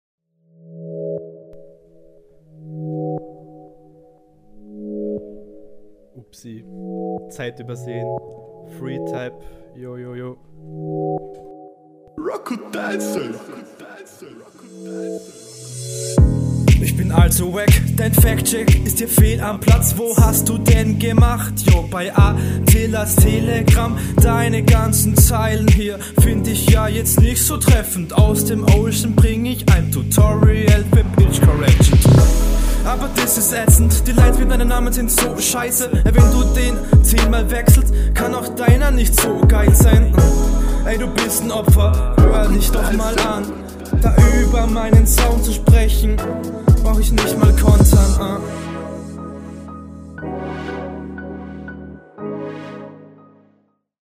höhen stechen bisi. aber grundsätzlich hast du auf jeden fall recht, das was du hier …